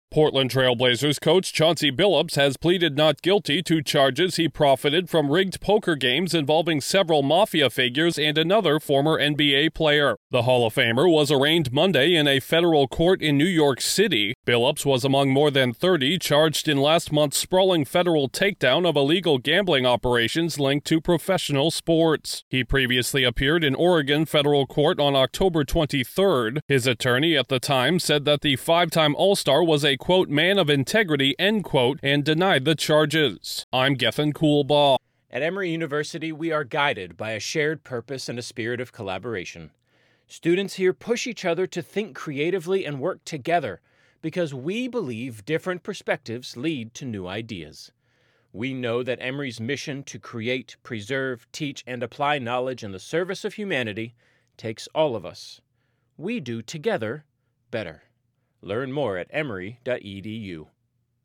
A key figure in last month’s NBA gambling scandal arrests has entered a plea in federal court. Correspondent